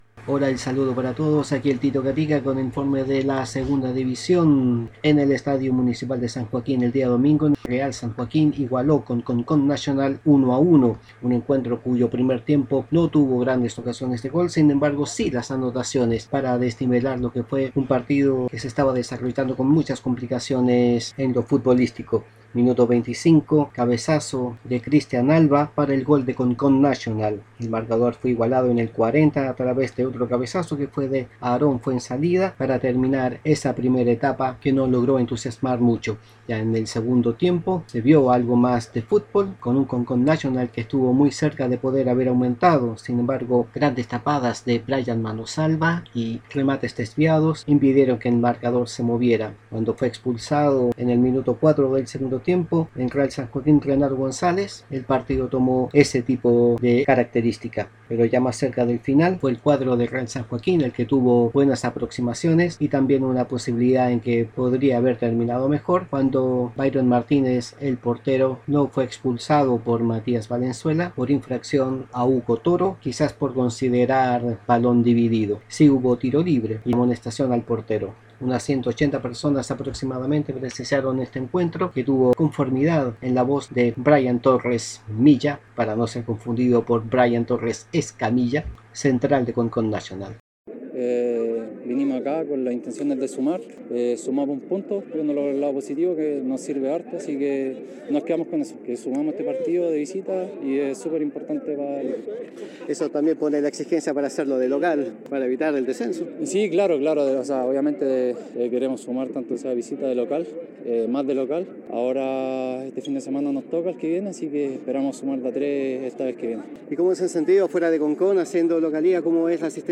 ABAJO> INFORME DEL PARTIDO Y SEGUNDA DIVISIÓN